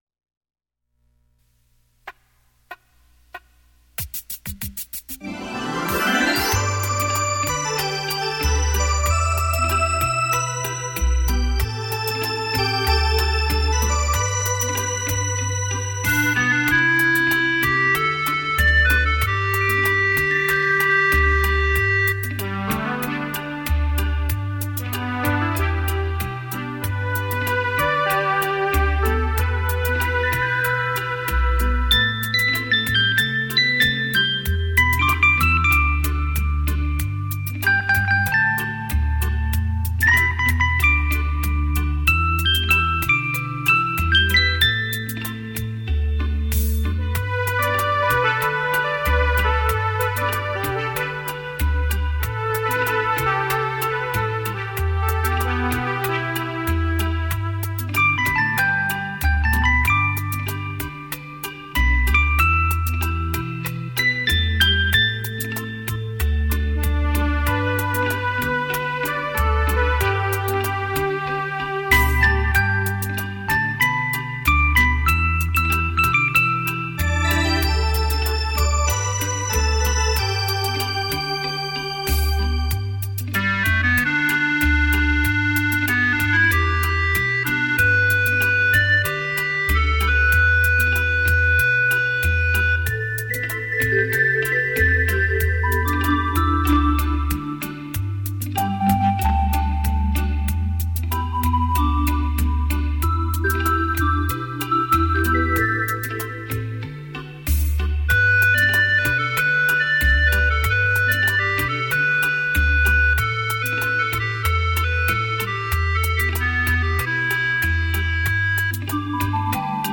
专辑类型：电子琴音乐